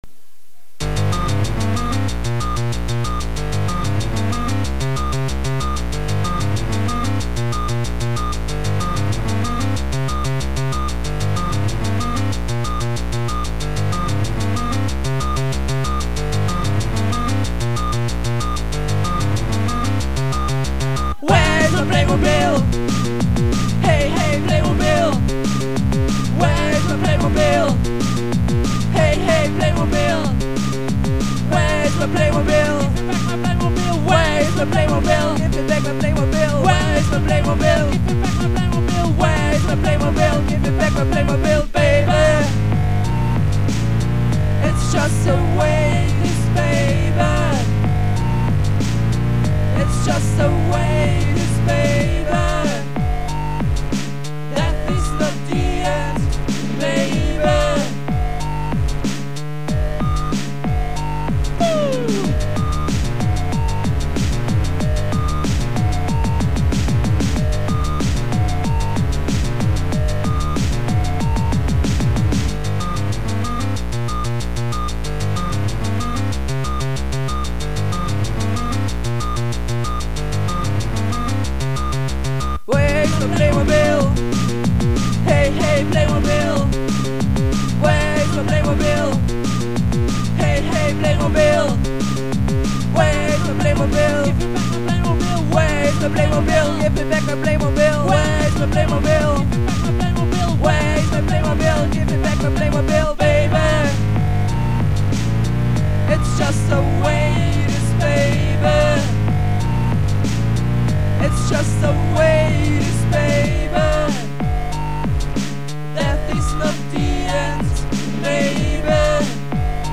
[ mp3 ] Very bad live rec.